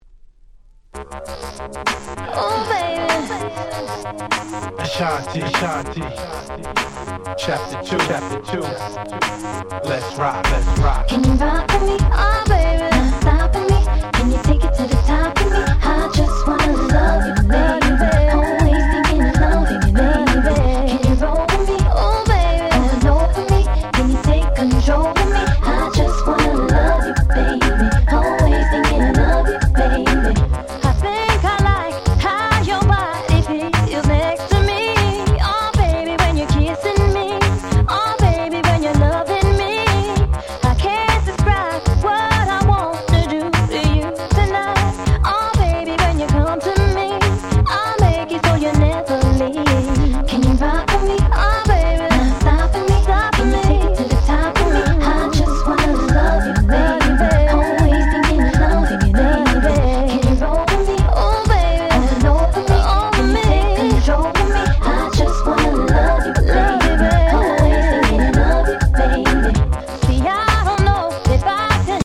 White Press Only Remix / Mash Up !!
Dancehall Reggae